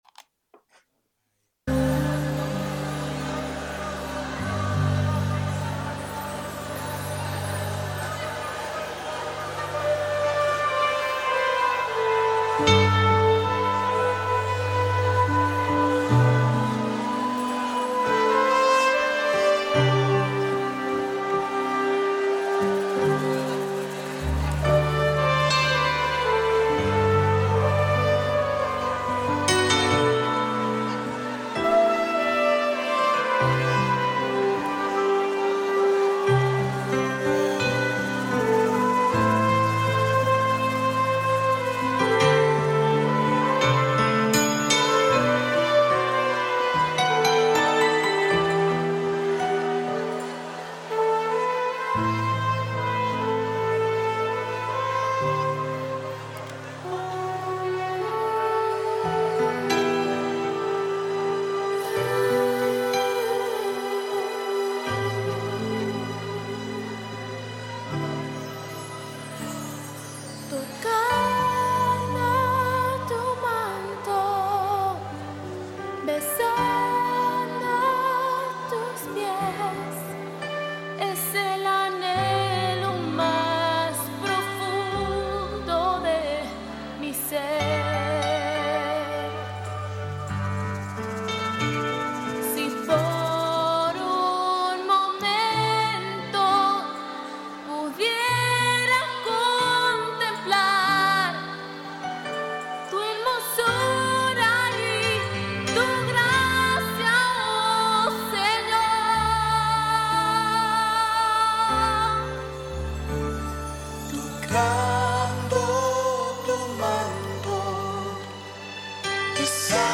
QUE SIGNIFICA CREER EN LA BIBLIA PARTE 2 PREDICA #4